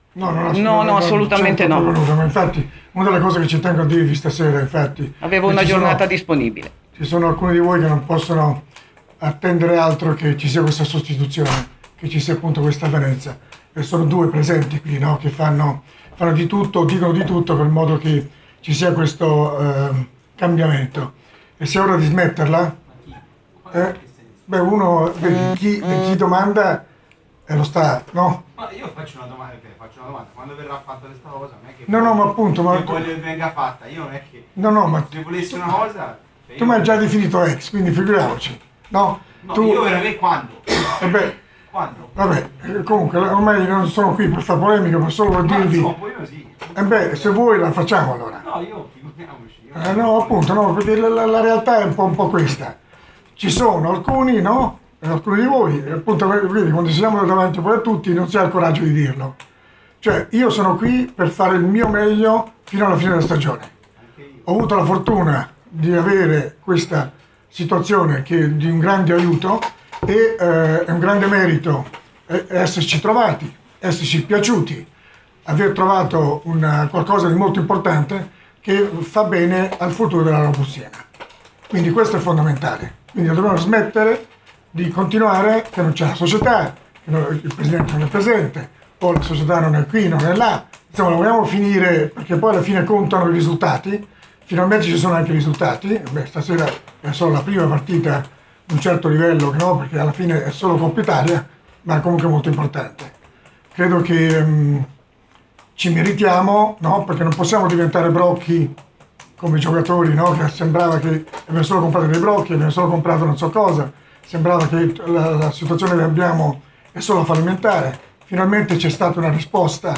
Robur Siena - Foggia 5-2, la conferenza fiume